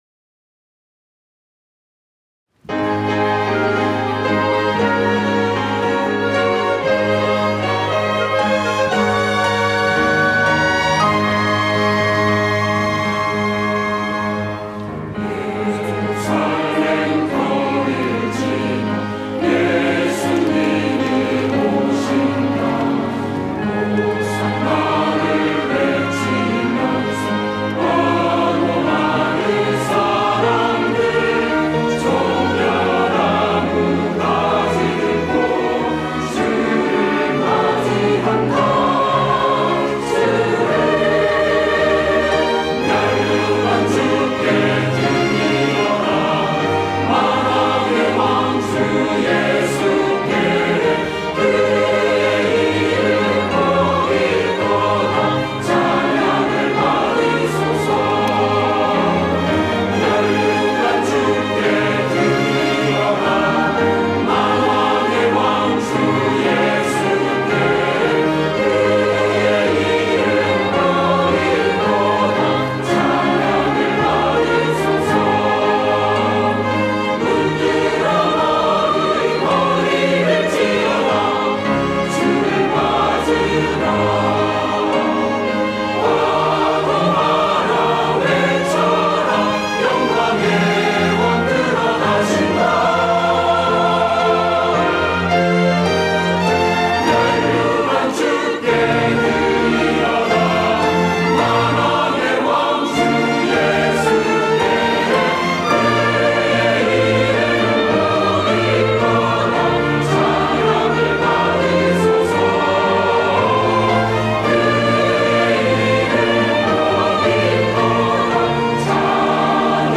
호산나(주일3부) - 면류관 드리세, 호산나
찬양대